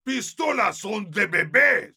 Voice file from Team Fortress 2 Spanish version.
Category:Heavy audio responses/es You cannot overwrite this file.